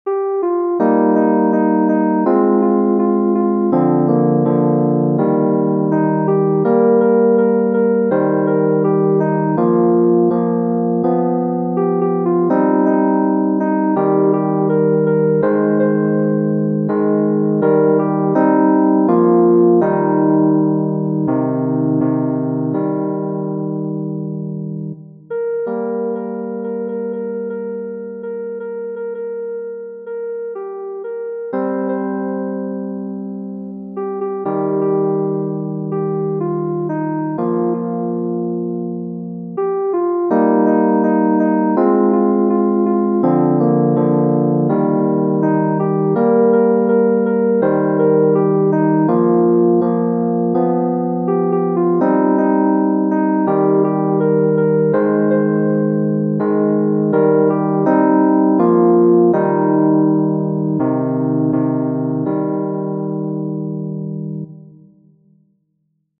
Genere: Religiose
canto liturgico